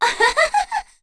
Kara-Vox_Happy2.wav